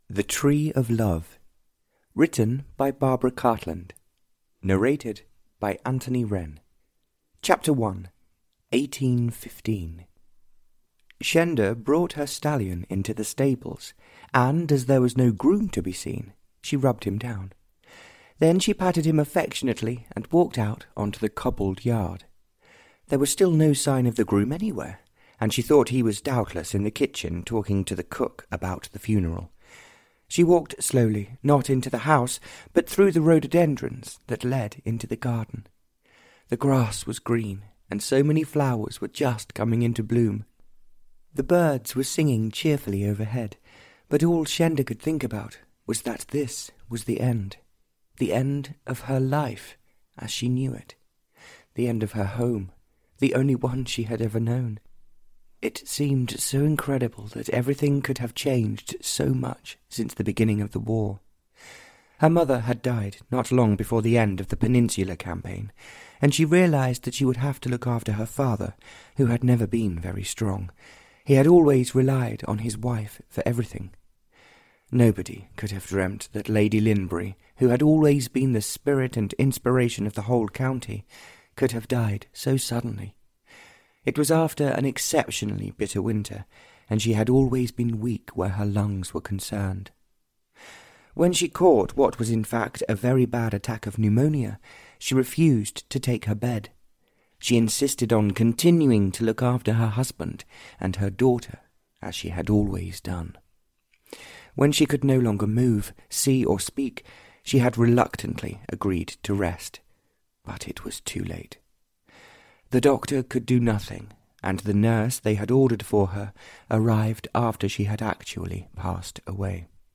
Audio knihaThe Tree of Love (Barbara Cartland s Pink Collection 74) (EN)
Ukázka z knihy